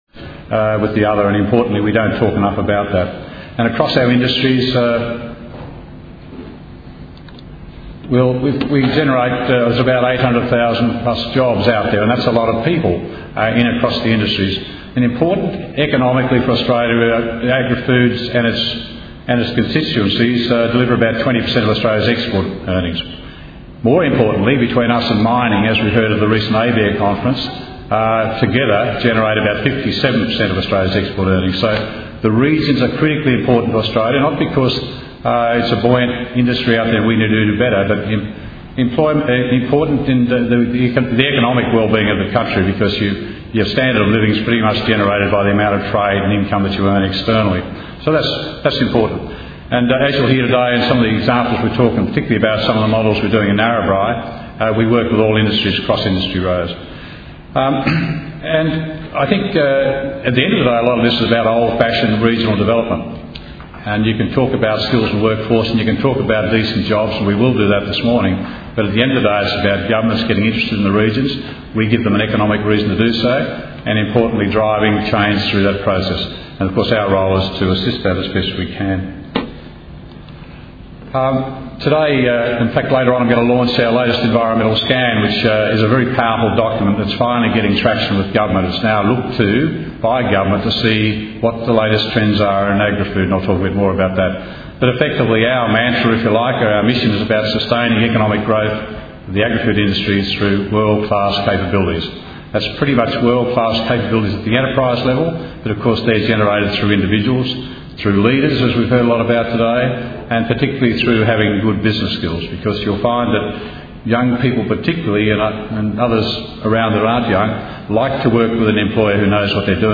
Taking a regional approach to rural workforces- Sustaining Rural Communities Conference 2010 Presentation Audio 21 min 5 mb | Inside Cotton